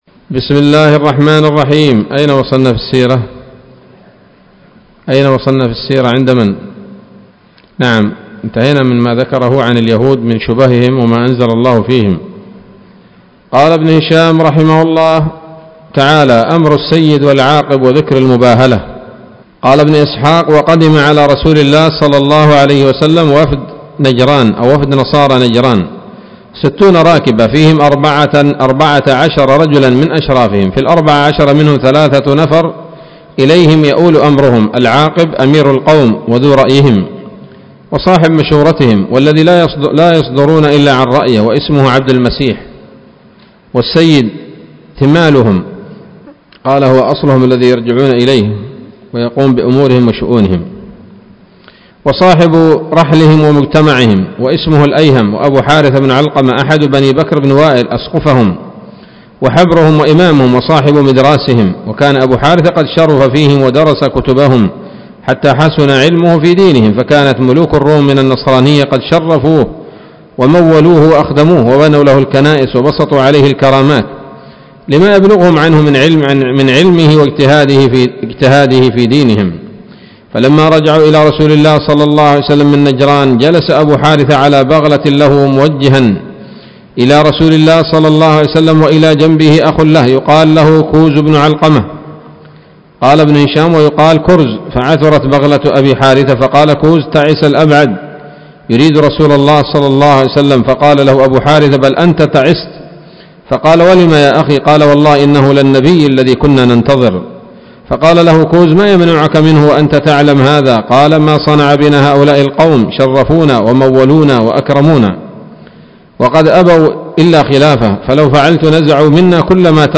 الدرس التاسع والتسعون من التعليق على كتاب السيرة النبوية لابن هشام